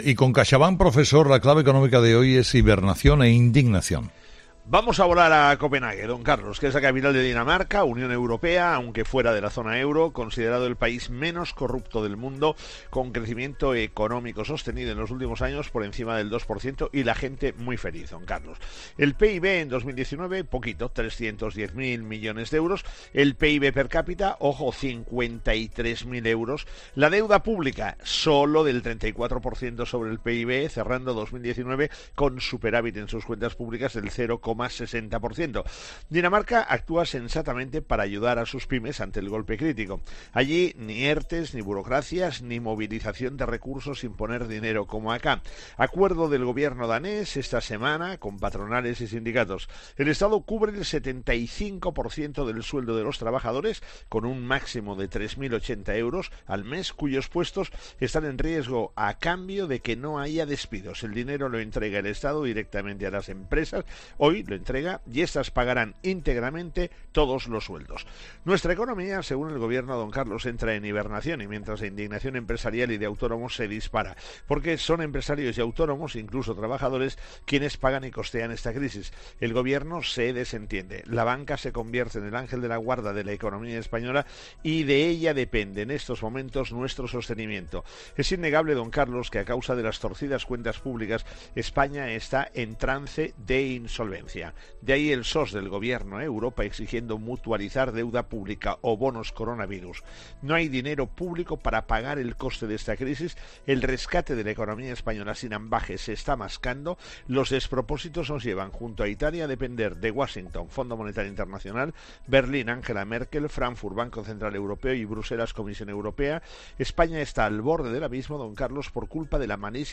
El profesor José María Gay de Liébana analiza en ‘Herrera en COPE’ las claves económicas del día.